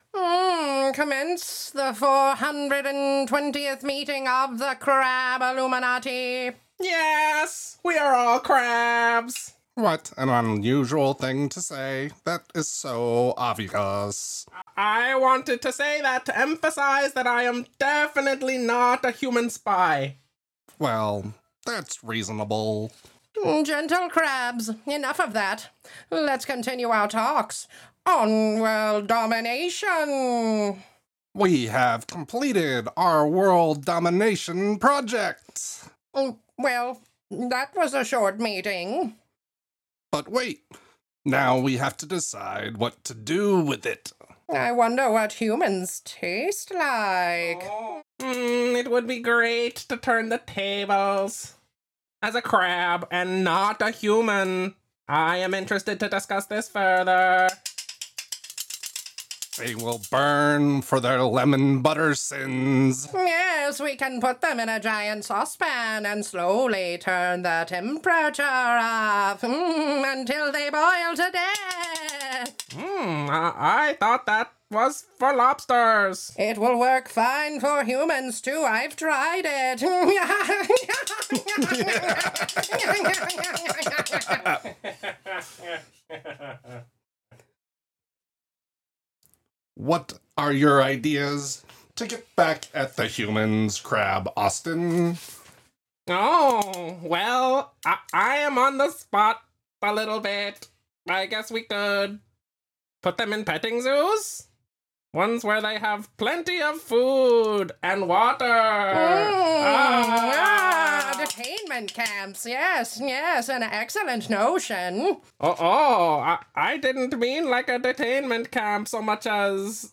Format: Audio Drama
Voices: Solo
Genres: Comedy